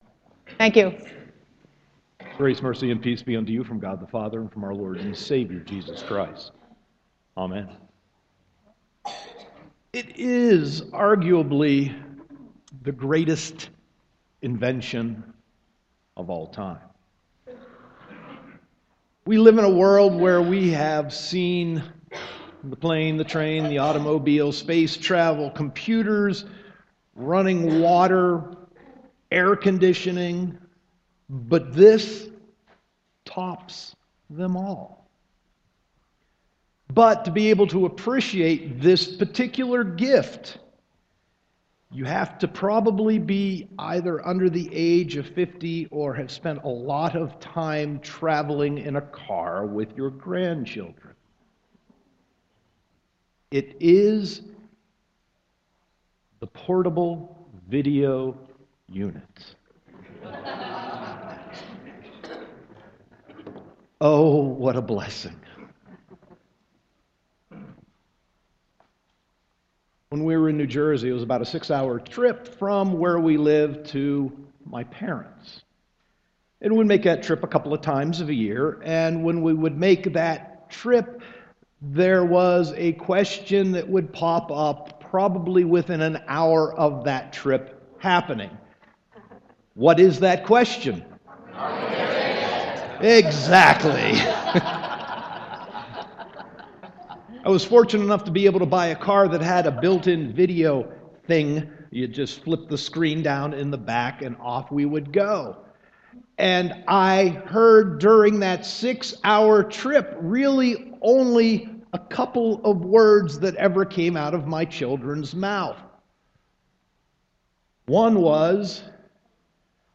Sermon 12.7.2014